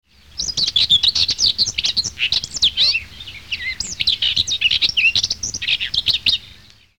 Fauvette grisette
Sylvia communis